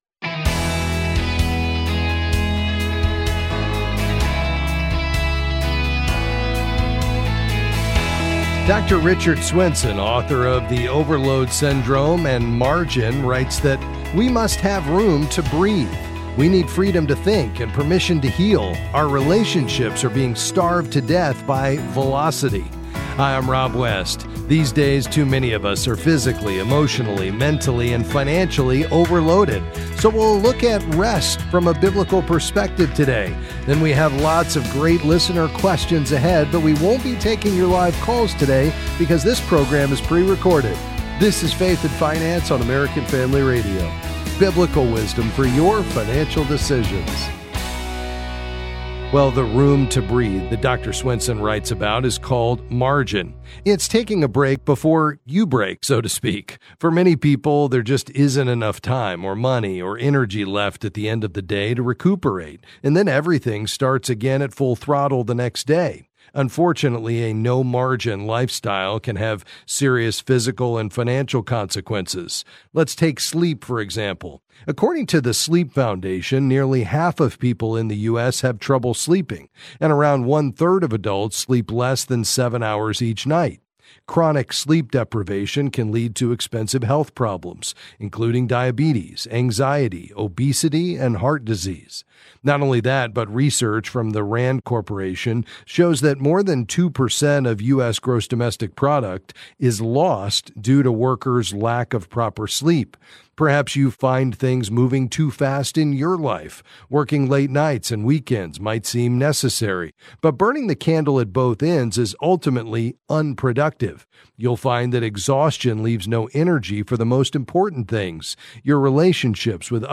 Then he’ll take some calls and answer various financial questions.